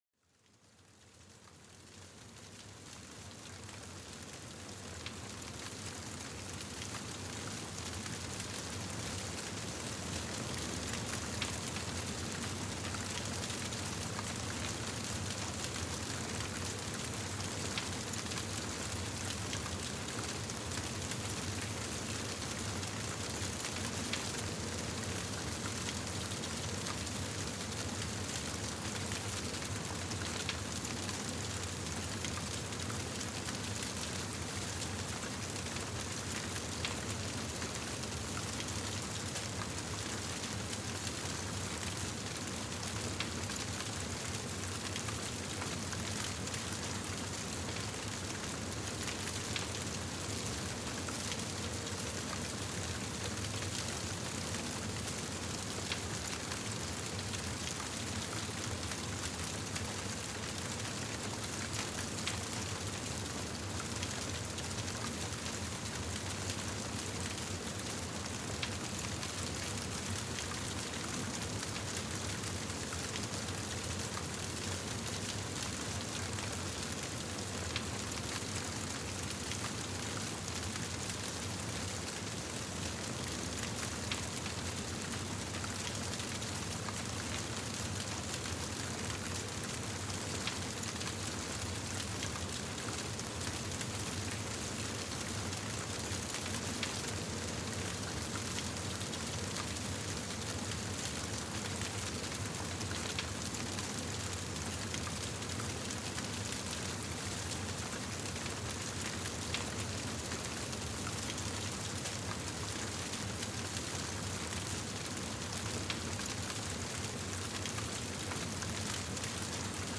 Plongez dans les crépitements réconfortants du feu dans cet épisode spécial. Découvrez comment le son des flammes peut réduire le stress et améliorer la relaxation.